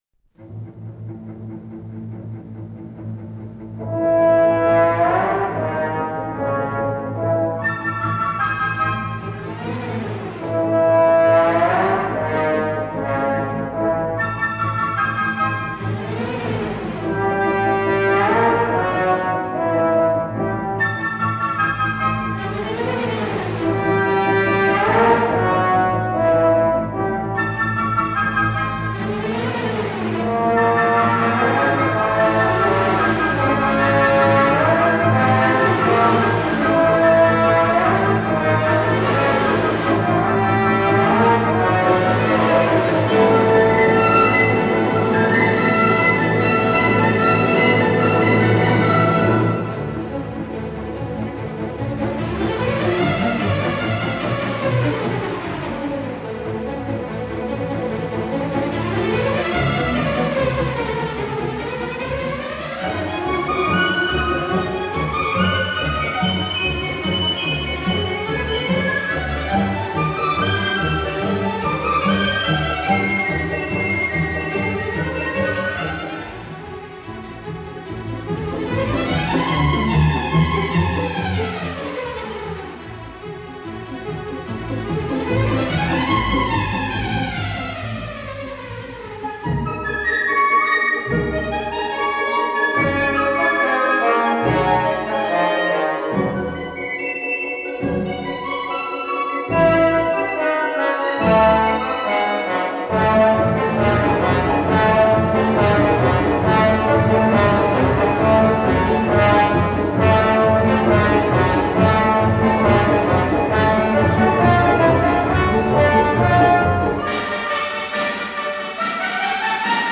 ВариантМоно